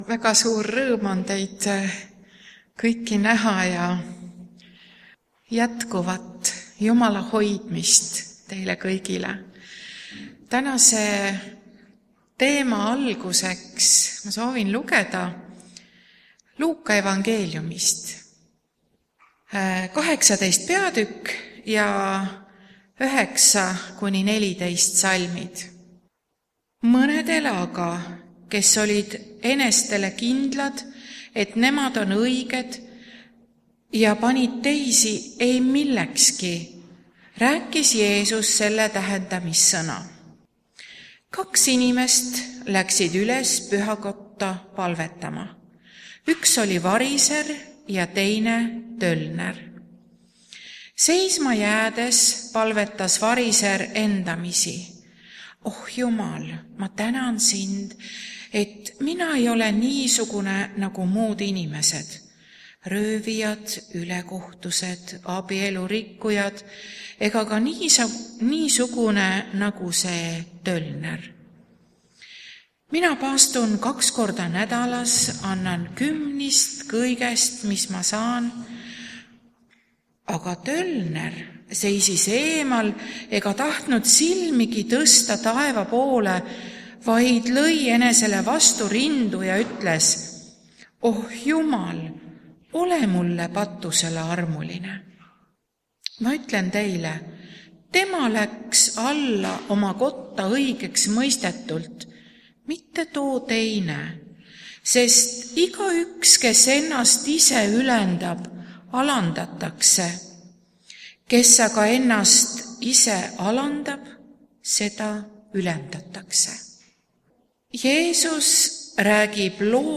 Muusikahetkeks vanem laul arhiivist
Jutlused